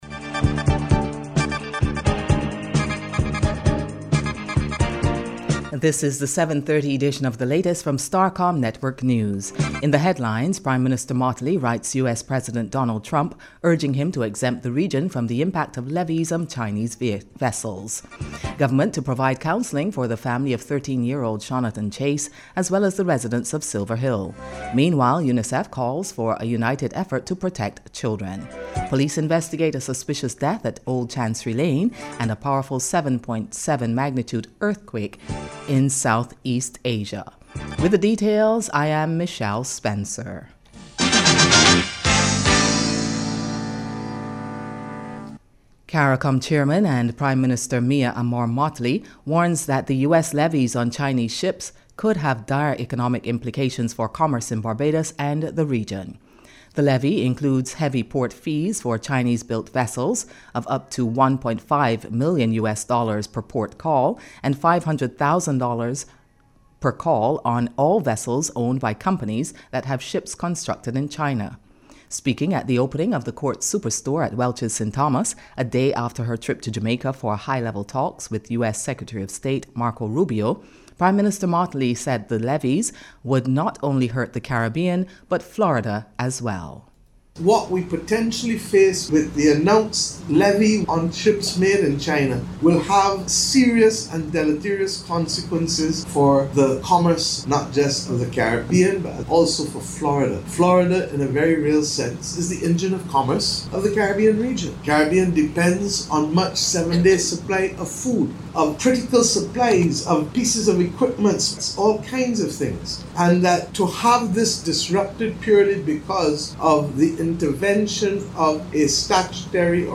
Miss Mottley outlined the plan at St. Peter Speaks, the latest in government’s series of public town-hall meetings as she responded to a complaint from a member of the public about service delivery at the QEH.